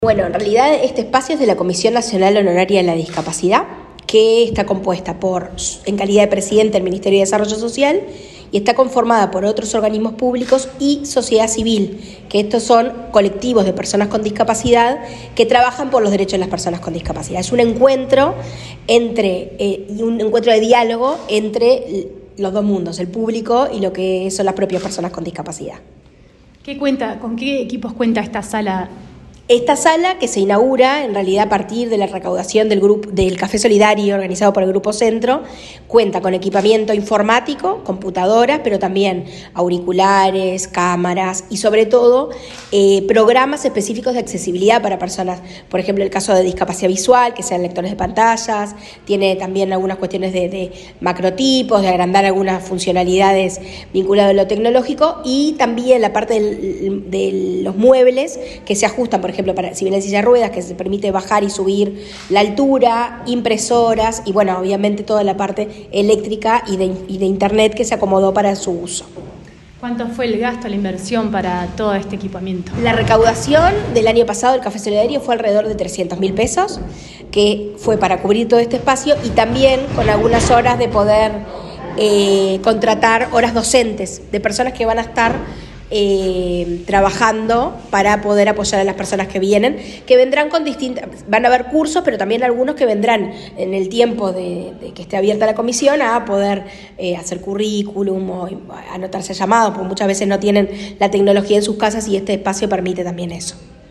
Entrevista a la directora de Discapacidad del Mides, Karen Sass